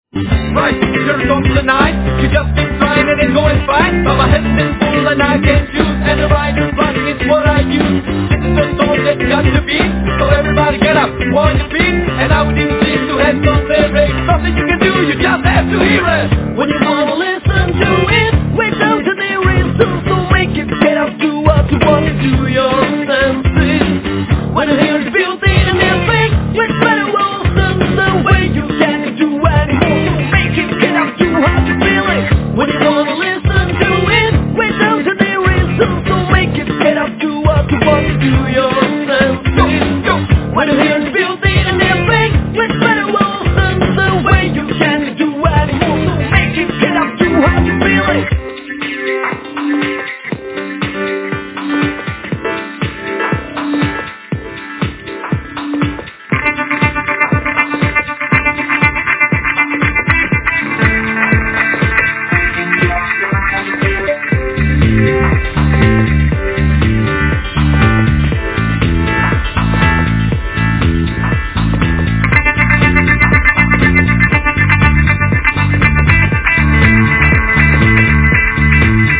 クールなクラブサウンドに時折絡むヘヴィーでパンキーなロックサウンドも非常にかっこいい！